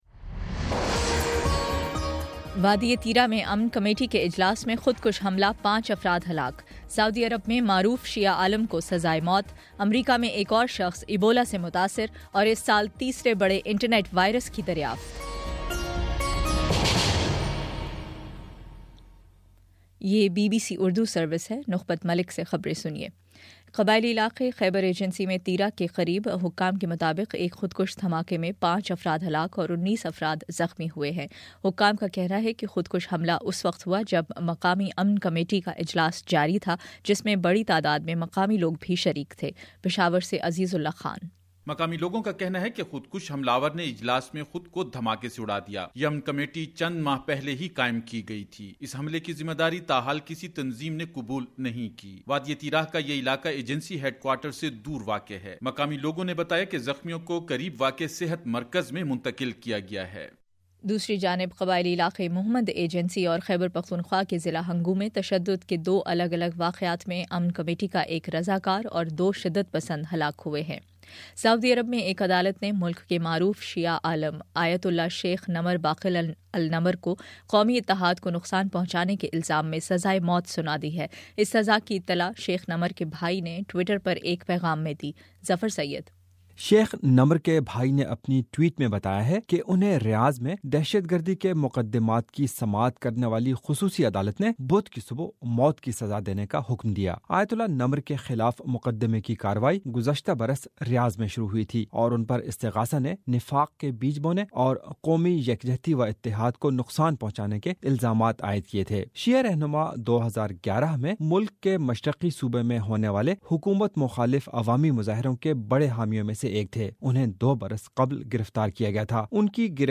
اکتوبر 15 : شام سات بجے کا نیوز بُلیٹن